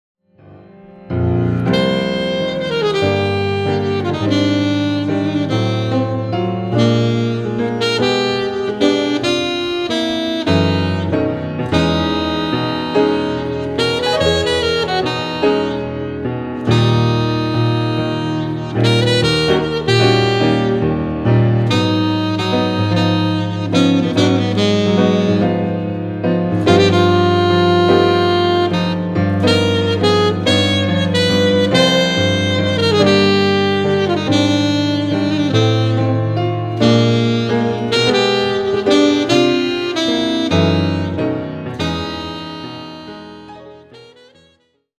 スリリングながらも暖かいJAZZ愛に溢れるDuo作品！
Alto Sax
Piano